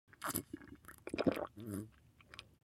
دانلود آهنگ آب 15 از افکت صوتی طبیعت و محیط
جلوه های صوتی
دانلود صدای آب 15 از ساعد نیوز با لینک مستقیم و کیفیت بالا